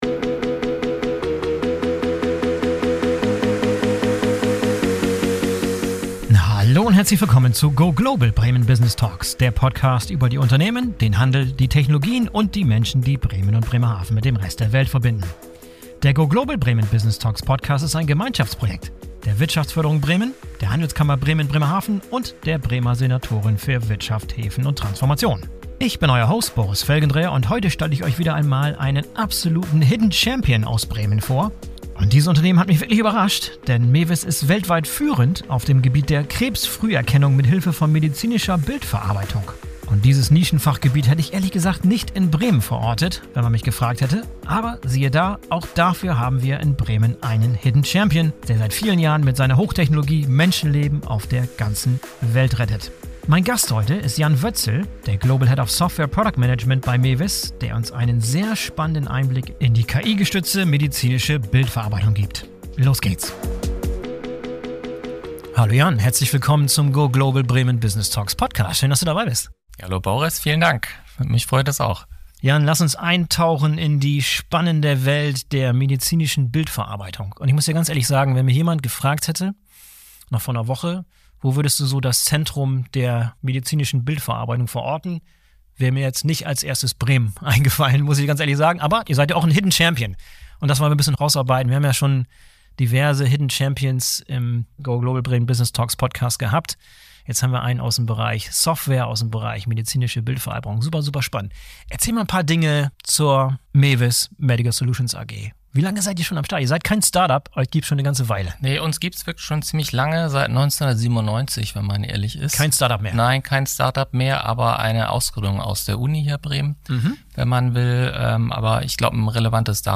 All das und mehr erfahrt ihr in diesem Gespräch